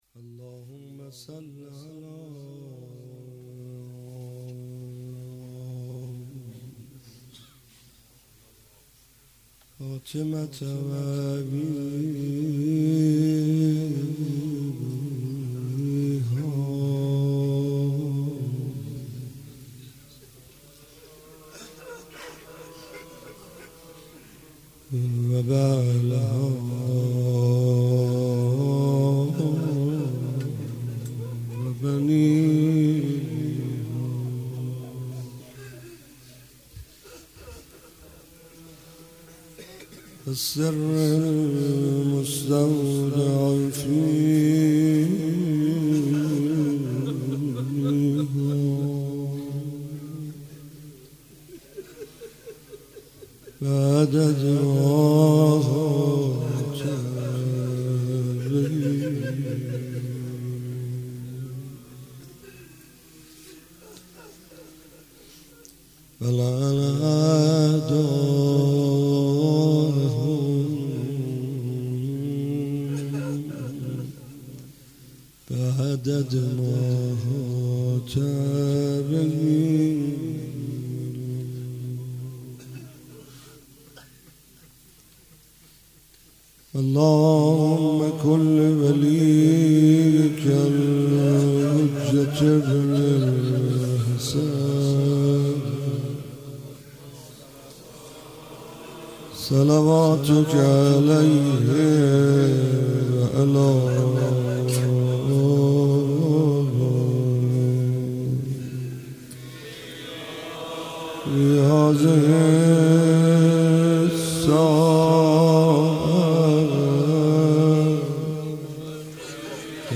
فاطمیه 95 - شب پنجم - مناجات - فراق تو بر دل شرر میگذارد